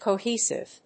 音節co・he・sive 発音記号・読み方
/koʊhíːsɪv(米国英語), kəʊhíːsɪv(英国英語)/